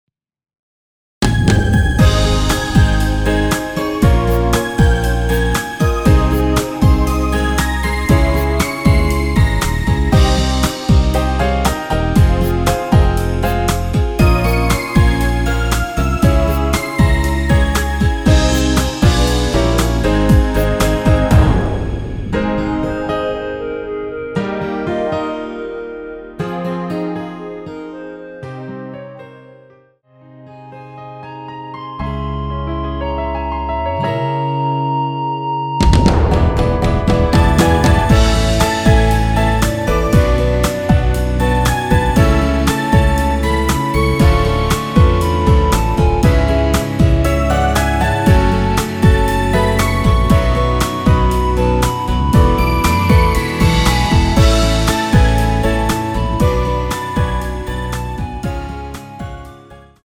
원키에서(+5)올린 멜로디 포함된 MR입니다.
Ab
앞부분30초, 뒷부분30초씩 편집해서 올려 드리고 있습니다.
중간에 음이 끈어지고 다시 나오는 이유는